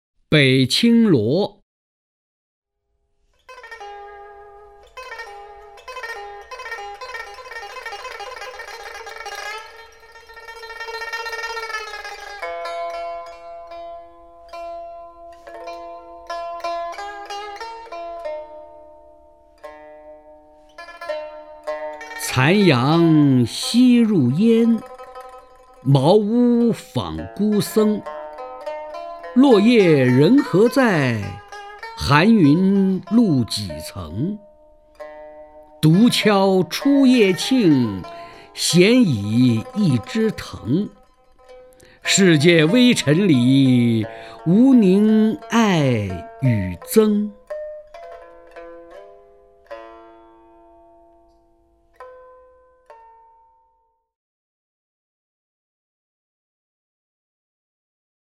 曹灿朗诵：《北青萝》(（唐）李商隐) （唐）李商隐 名家朗诵欣赏曹灿 语文PLUS